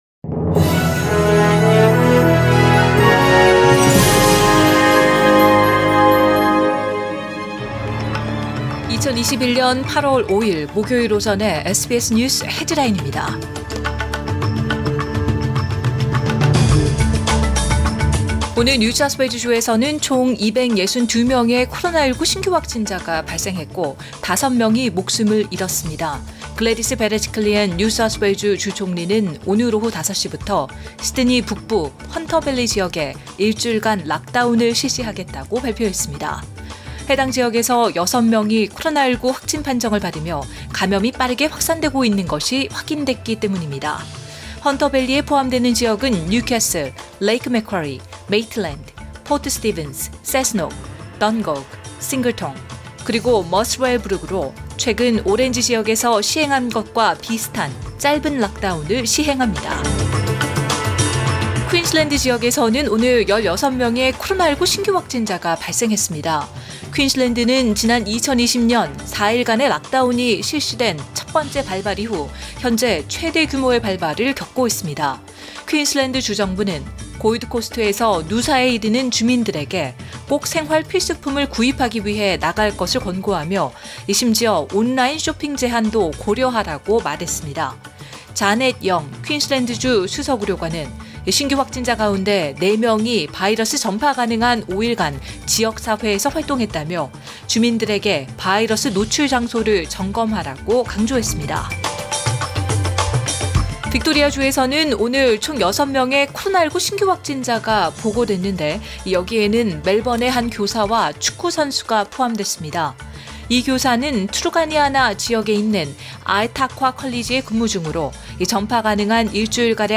2021년 8월 5일 목요일 오전의 SBS 뉴스 헤드라인입니다.